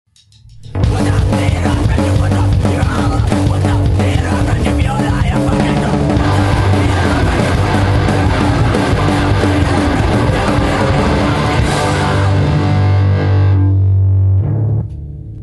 They are a very tongue-in-cheack hardcore band.